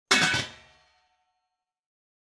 Kitchen Pot Lid Close Wav Sound Effect #2
Description: The sound of putting a lid on the kitchen pot
Properties: 48.000 kHz 16-bit Stereo
Keywords: kitchen pot, pan, lid, metallic, metal, cover, cooking, put, place, placing
pot-lid-close-preview-2.mp3